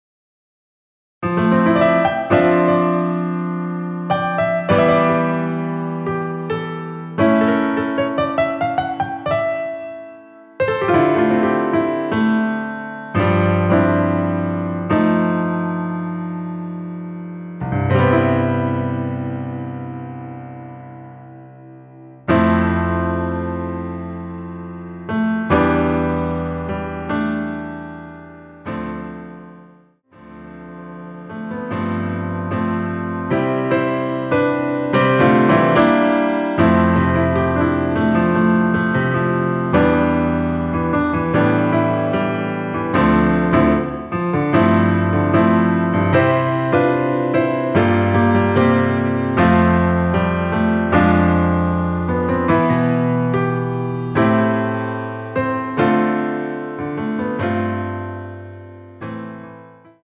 축가로도 잘 어울리는 곡
앞부분30초, 뒷부분30초씩 편집해서 올려 드리고 있습니다.
중간에 음이 끈어지고 다시 나오는 이유는